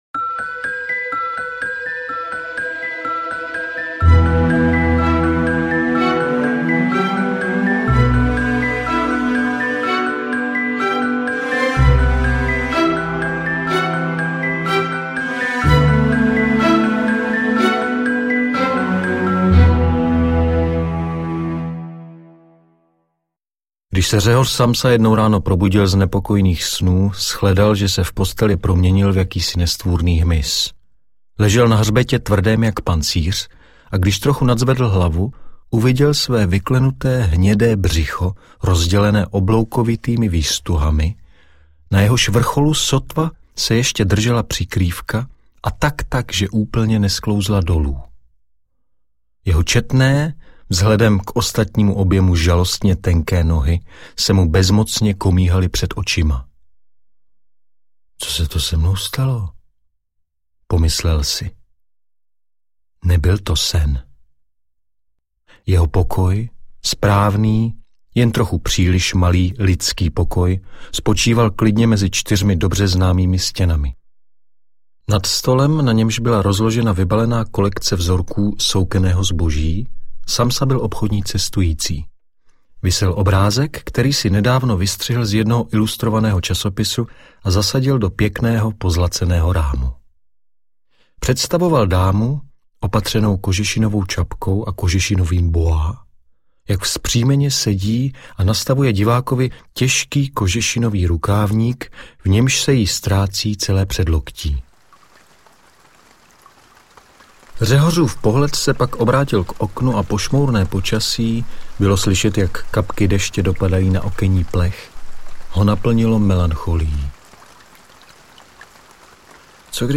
Interpret:  Jan Budař
AudioKniha ke stažení, 10 x mp3, délka 2 hod. 4 min., velikost 112,9 MB, česky